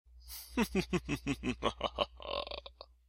Basic evil laugh
evil laugh.ogg